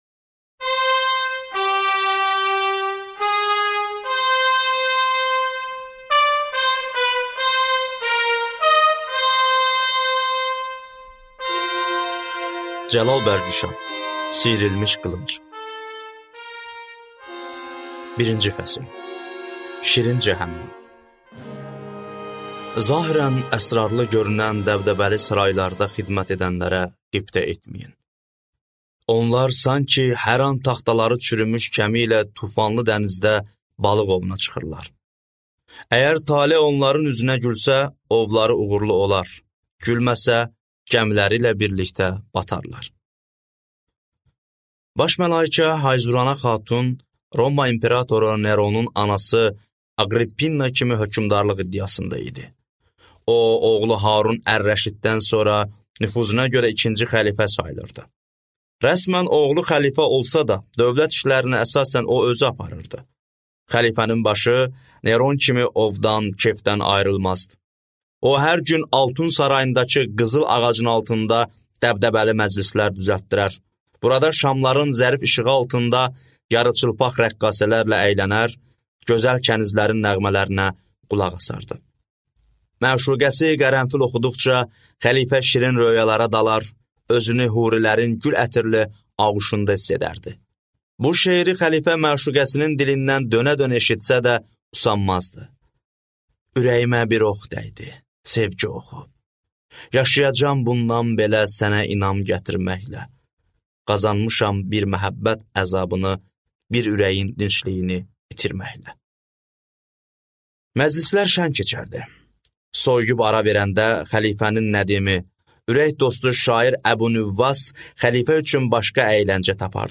Аудиокнига Sıyrılmış qılınc | Библиотека аудиокниг